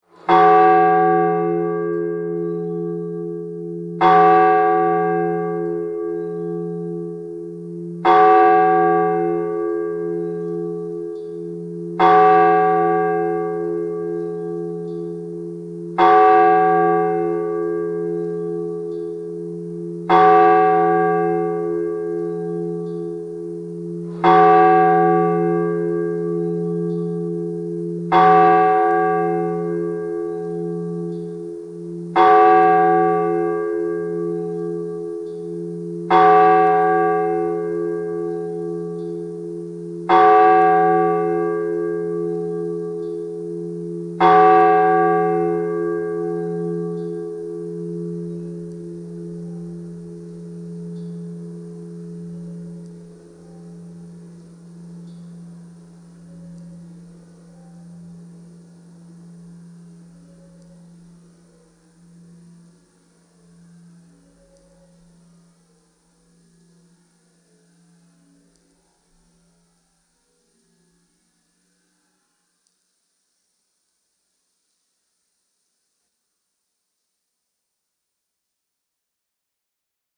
meadow ambience